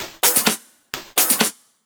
Index of /VEE/VEE2 Loops 128BPM
VEE2 Electro Loop 307.wav